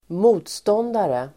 Uttal: [²m'o:tstån:dare]